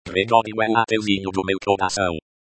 Gerar áudio do texto que você quiser após a palavra "diga".